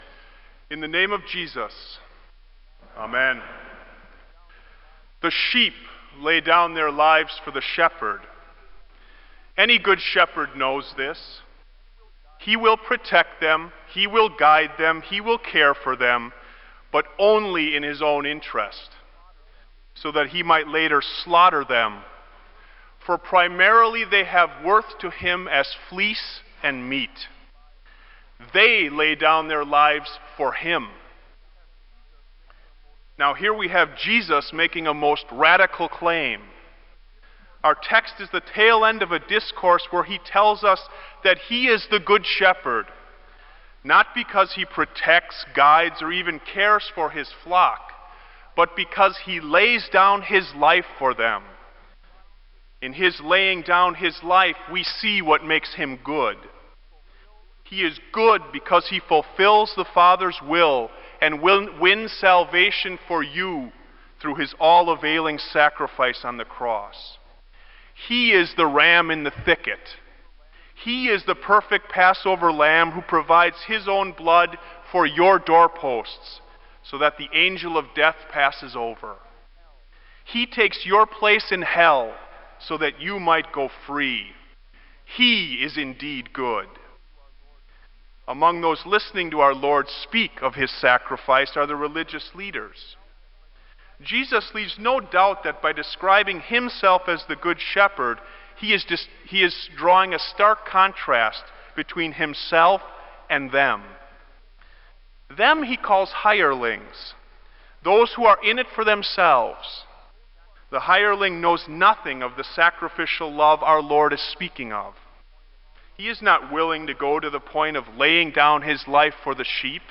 Kramer Chapel Sermon - April 07, 2000